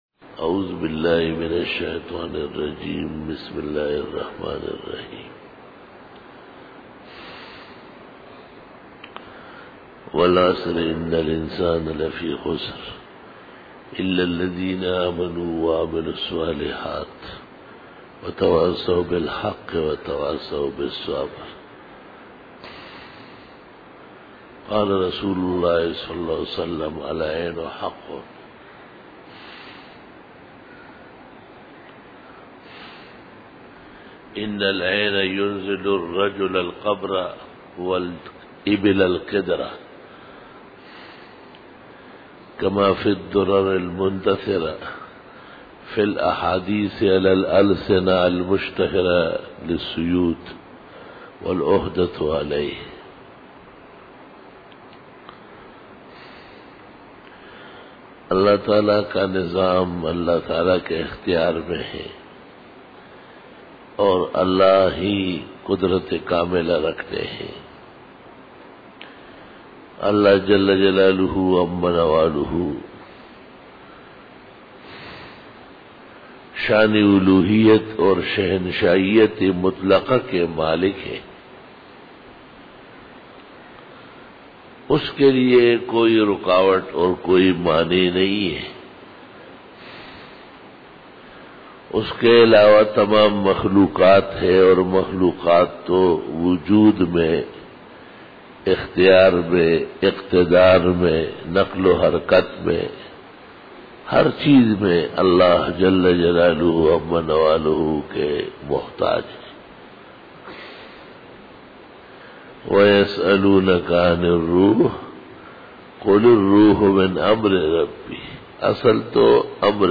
Khitab-e-Jummah